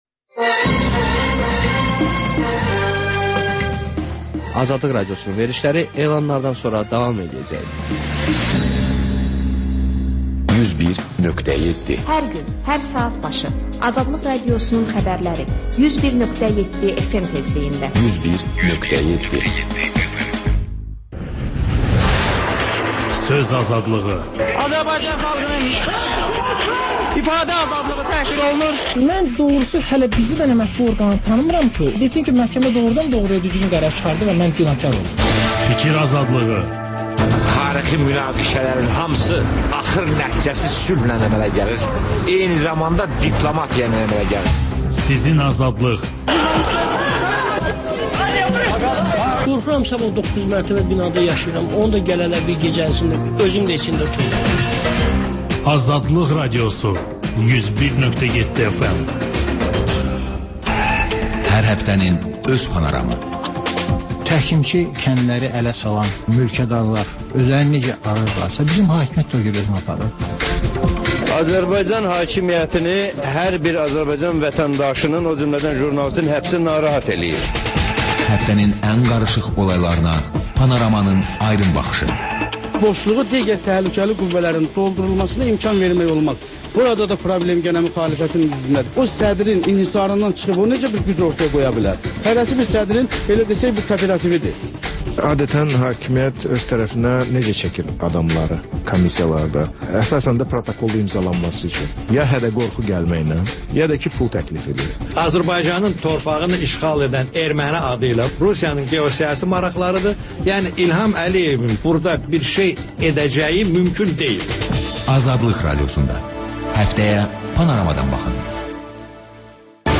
Xəbərlər, XÜSUSİ REPORTAJ: Ölkənin ictimai-siyasi həyatına dair müxbir araşdırmaları və TANINMIŞLAR rubrikası: Ölkənin tanınmış simaları ilə söhbət